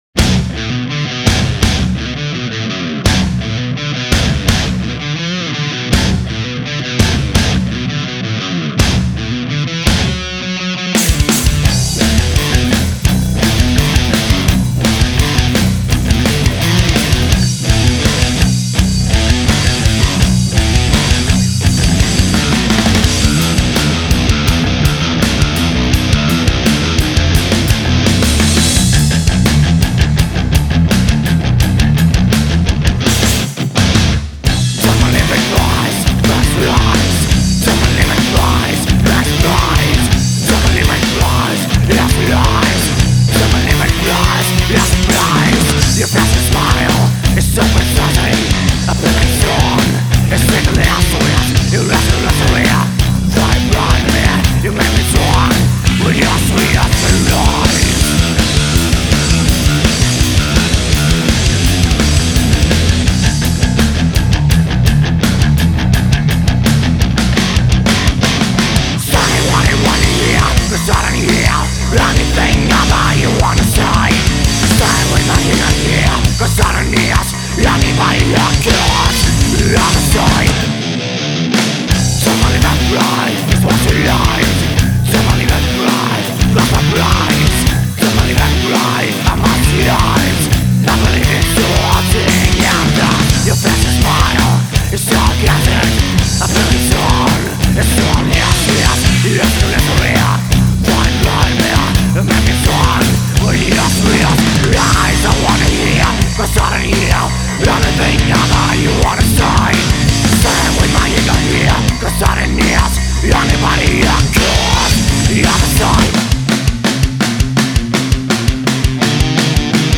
Bet nu ierkastā skan ļoti labi un pārliecinoši.